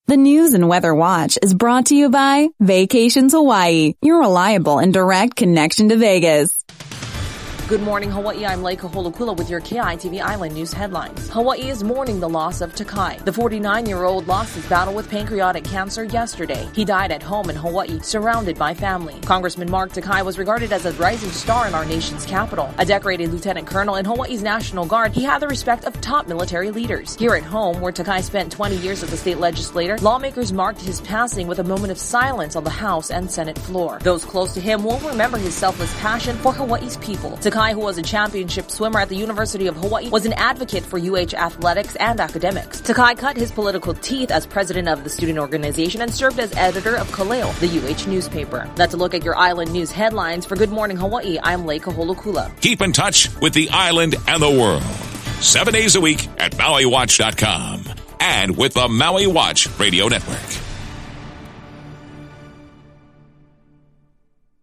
Your daily news brief for July 21, 2016